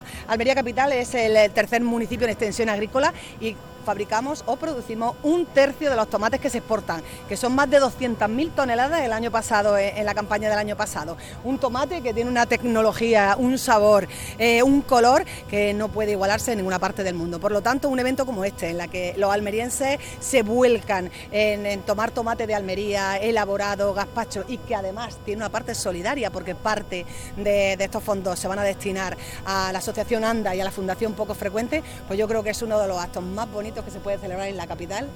21-02_dia_del_tomate__maria_del_mar_vazquez.mp3